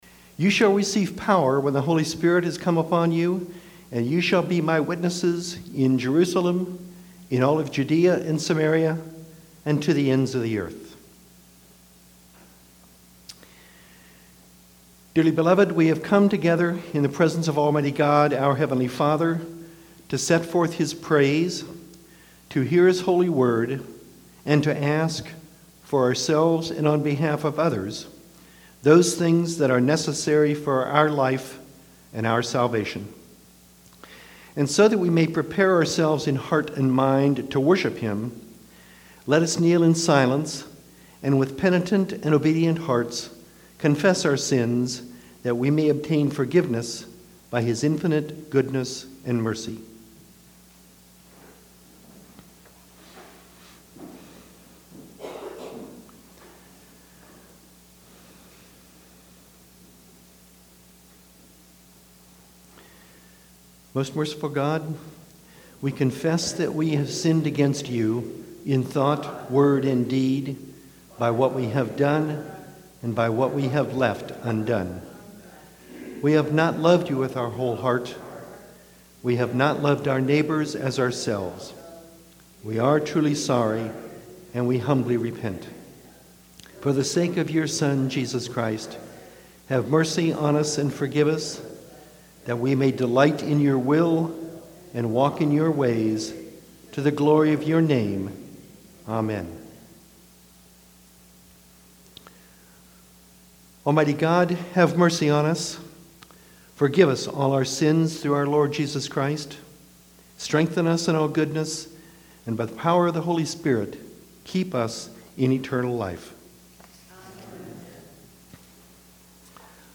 Sermon – November 10, 2019